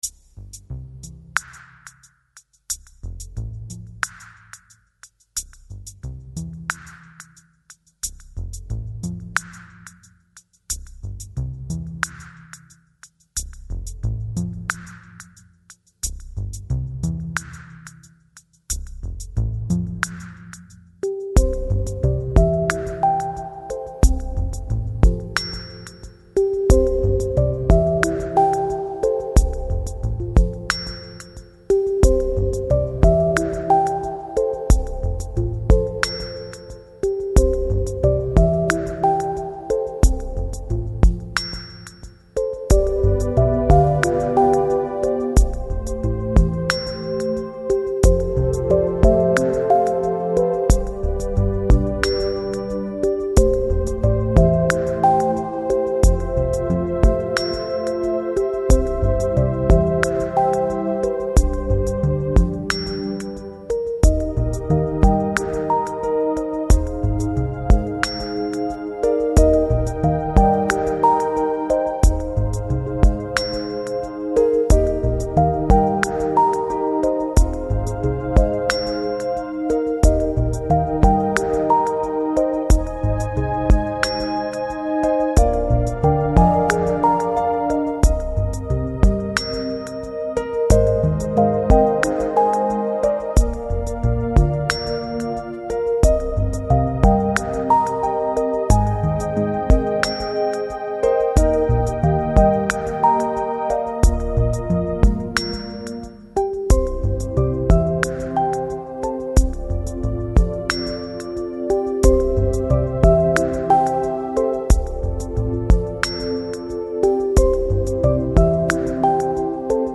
Жанр: Downtempo, Chill Out